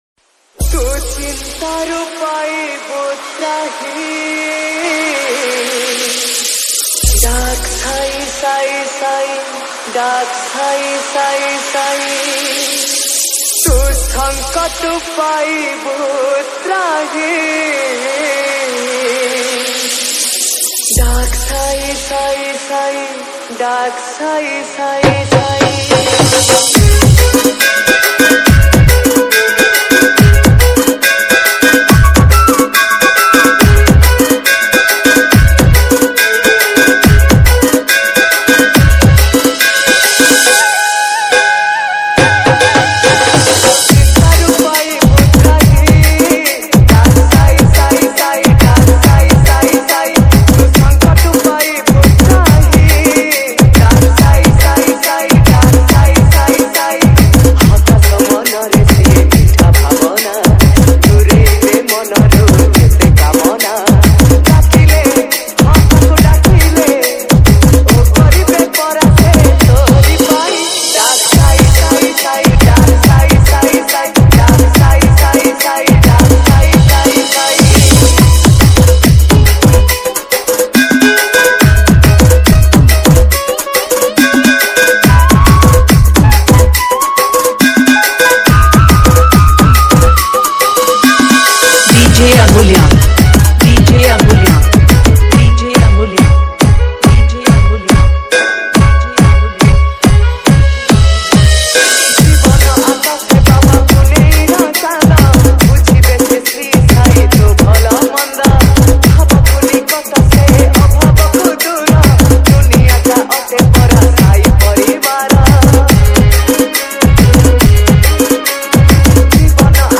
ODIA BHAJAN DJ REMIX